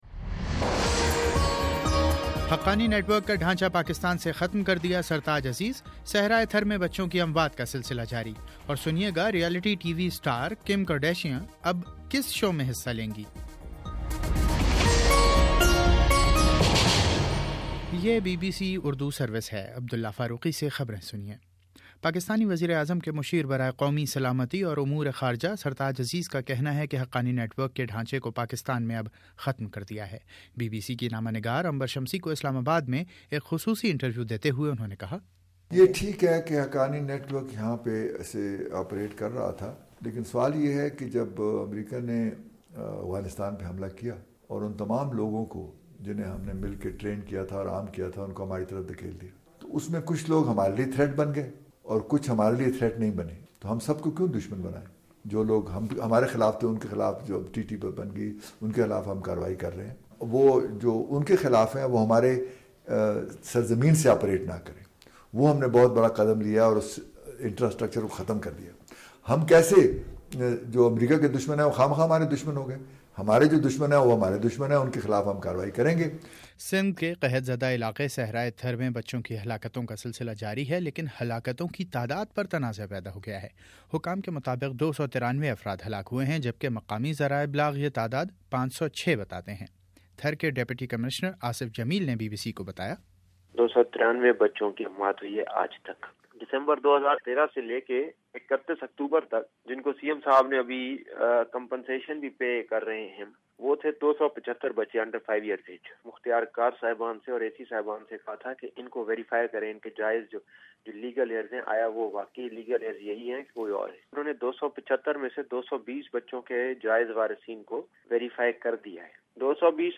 نومبر 17: شام سات بجے کا نیوز بُلیٹن
دس منٹ کا نیوز بُلیٹن روزانہ پاکستانی وقت کے مطابق صبح 9 بجے، شام 6 بجے اور پھر 7 بجے۔